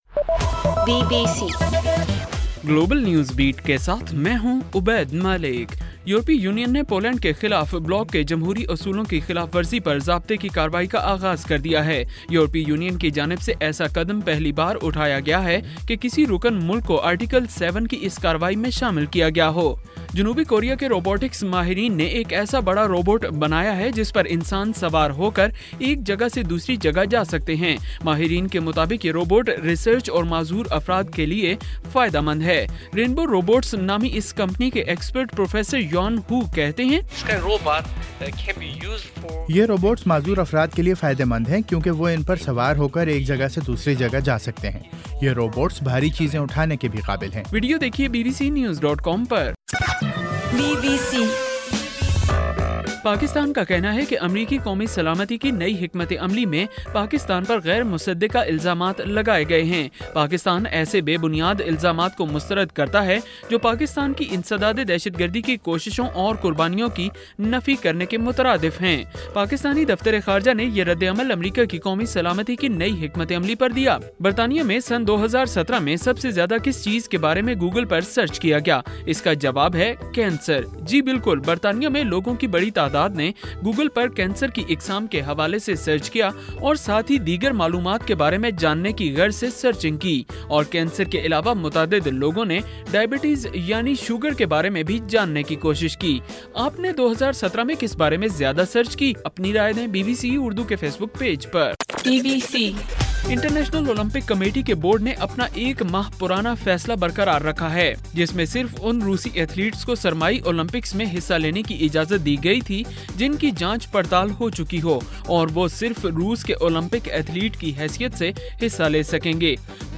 گلوبل نیوز بیٹ بُلیٹن اُردو زبان میں رات 8 بجے سے صبح 1 بجے ہرگھنٹےکے بعد اپنا اور آواز ایفایم ریڈیو سٹیشن کے علاوہ ٹوئٹر، فیس بُک اور آڈیو بوم پر